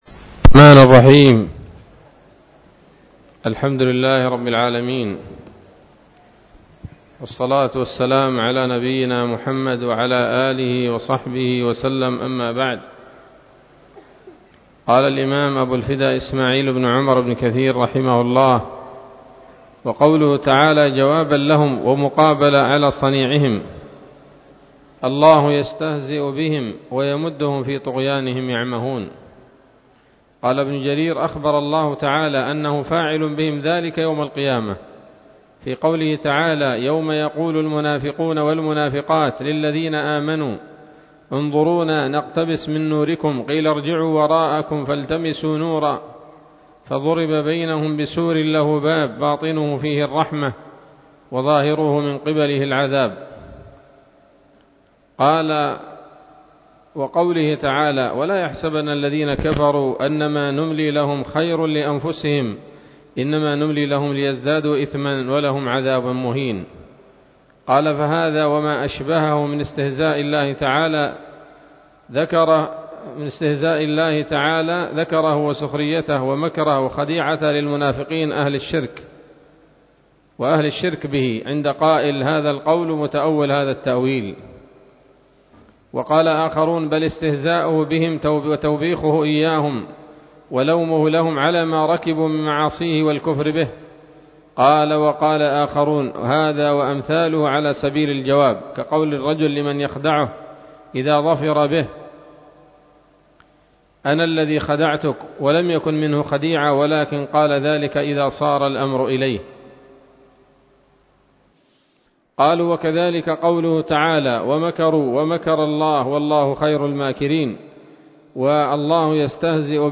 الدرس الثامن عشر من سورة البقرة من تفسير ابن كثير رحمه الله تعالى